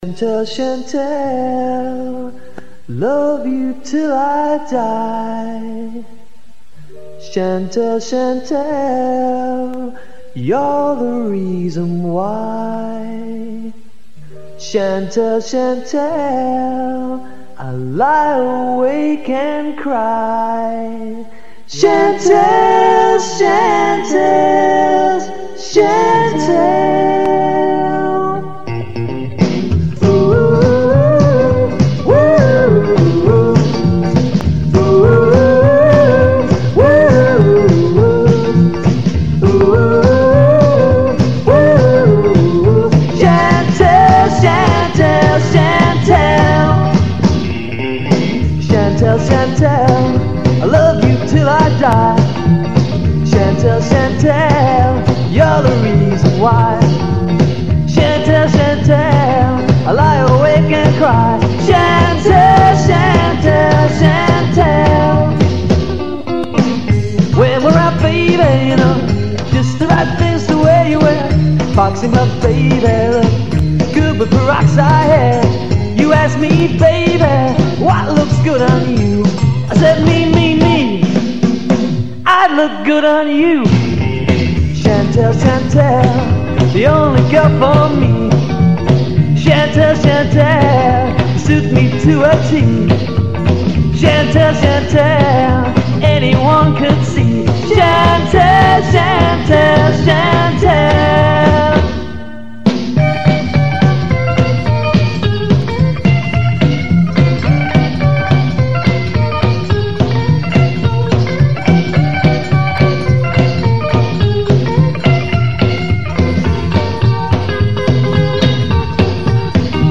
The Incurable, a 1980s British rockabilly trio
They invented and made their own double bass.
cute doo-wop, firmly stucks in your head